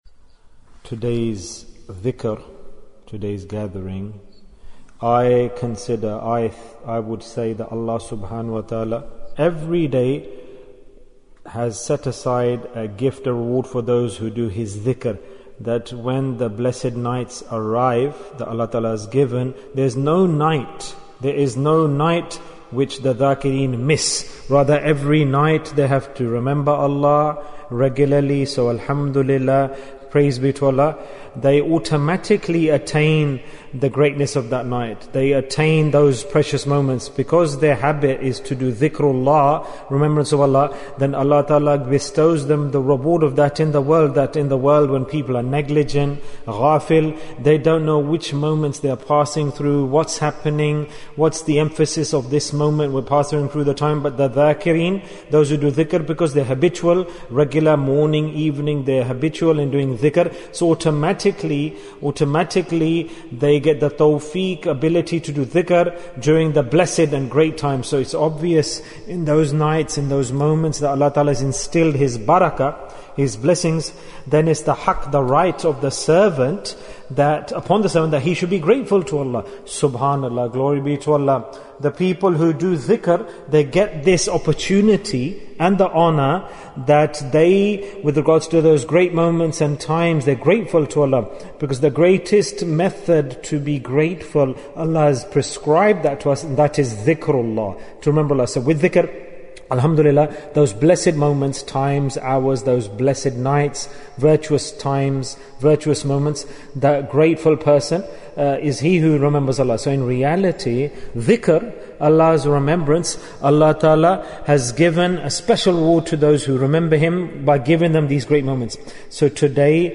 The Month of Spring Bayan, 12 minutes17th October, 2020